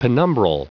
Prononciation du mot penumbral en anglais (fichier audio)